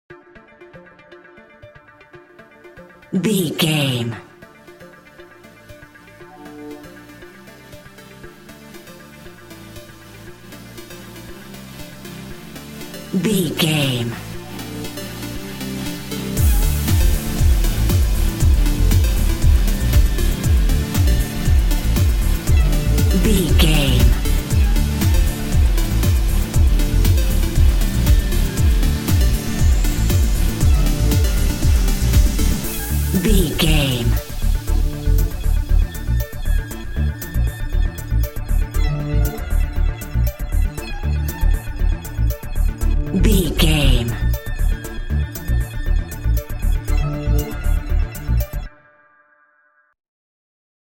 Pop Chart Electronic Dance Music 30 Sec.
Fast paced
In-crescendo
Aeolian/Minor
groovy
uplifting
driving
energetic
bouncy
synthesiser
drum machine
house
electro dance
synth leads
synth bass
upbeat